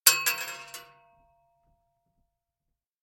Bullet Shell Sounds
rifle_metal_4.ogg